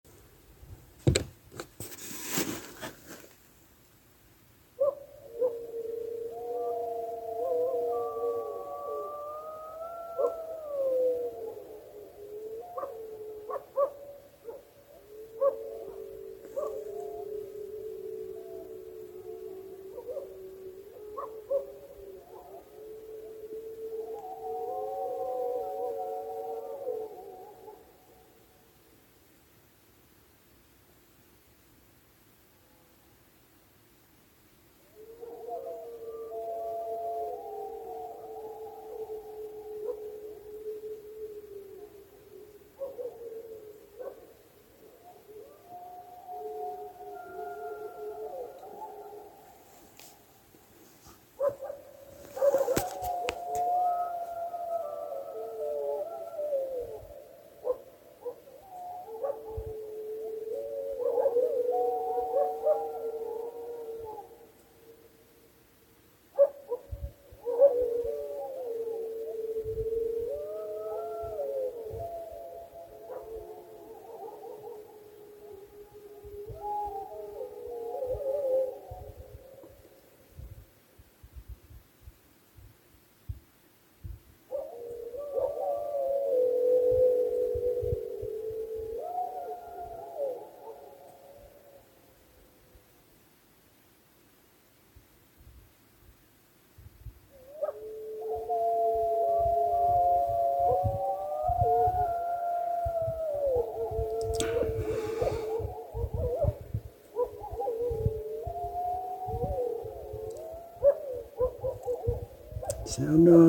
Listen to an audio recording of Mexican Gray Wolves! I made this recording late at night on Oct. 18, 2023 while camping near Reservation Lake on the Fort Apache Reservation in the White Mts. It's just cell phone video so the sound quality is not great, and since it was dark there is no visual to accompany the sound. Still, you can easily hear an entire chorus of wolves howling. In addition to howling you can hear barking, which may sound like domestic dogs but is in fact also the wolves.
Wolves Audio.m4a